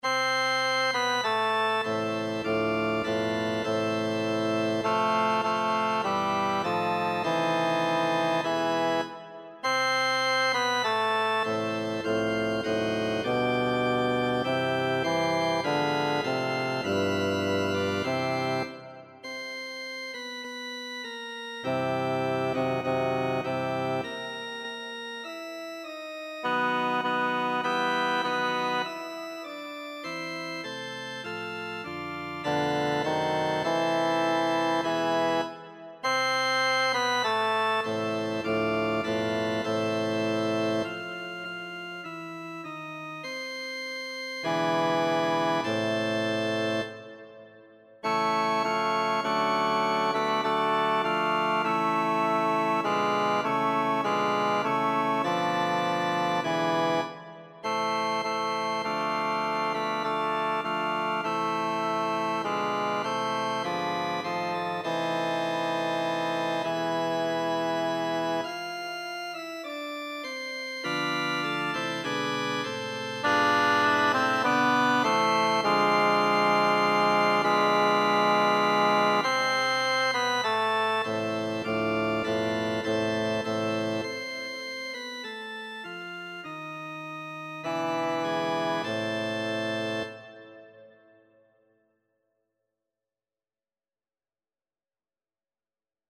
Bass
abendlich-schon-rauscht-der-wald-bass.mp3